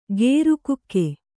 ♪ gēru kukku